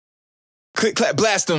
Nasty Hybrid Trap Vocal
描述：The last nasty loop The predrop vocal
标签： 150 bpm Trap Loops Vocal Loops 275.80 KB wav Key : Unknown
声道立体声